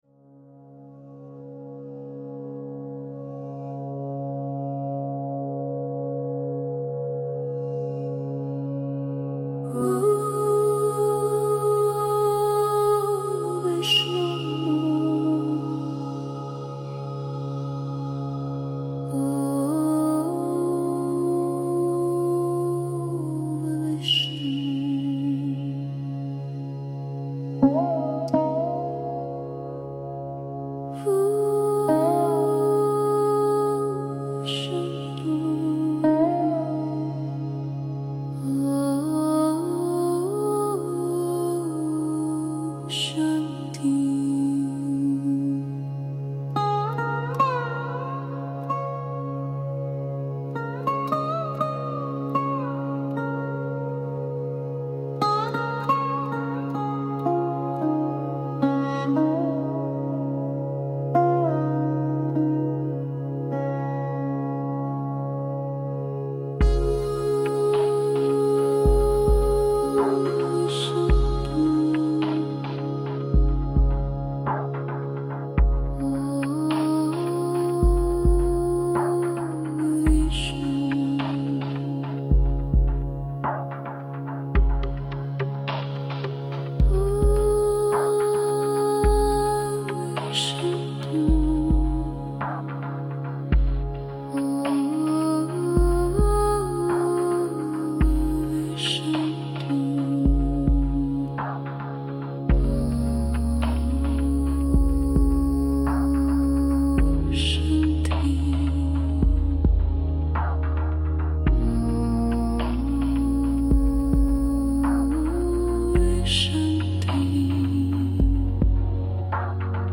India-style Lo-Fi tracks
chant (soft male/female vocals)